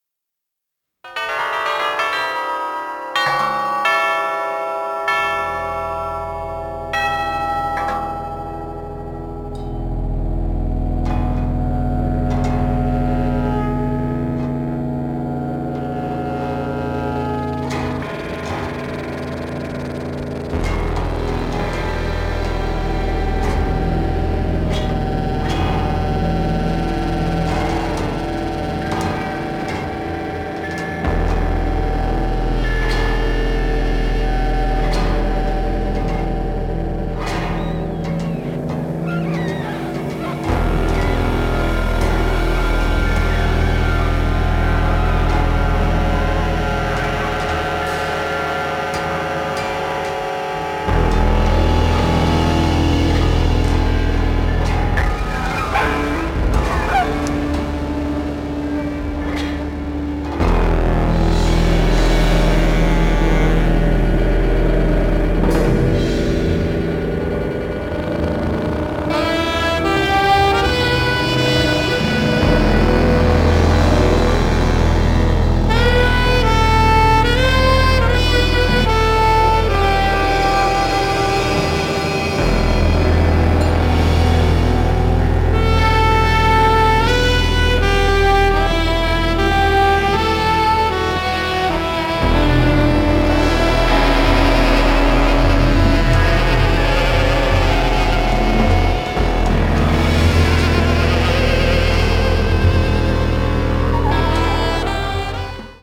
sopranino & alto saxophones
sound design & libretto
piano
cello, electric fretless bass, percussion
drums
electric guitar
soprano & tenor saxophones
alto & barytone saxophones
trombone
trumpet, cornet
Recorded at "La Fabrique", Meung-sur-Loire, France,